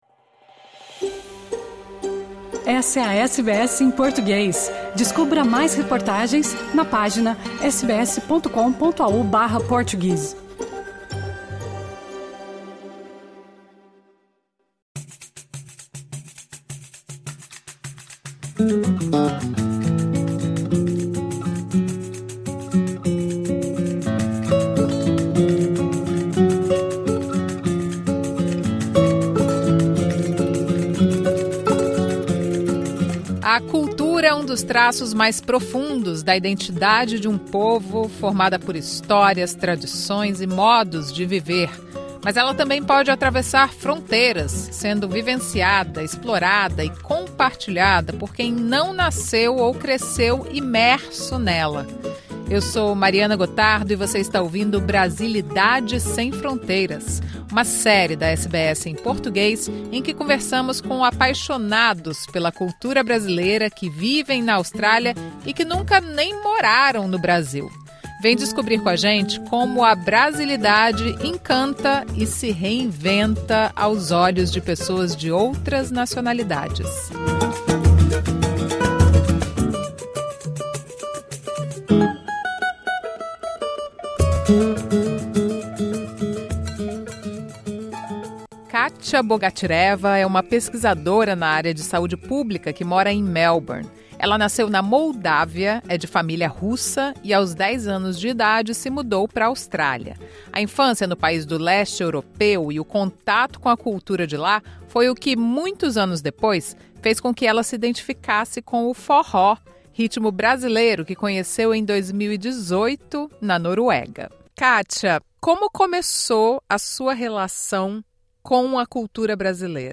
Nesta série trazemos entrevistas com estrangeiros que amam, consomem e divulgam a cultura brasileira na Austrália.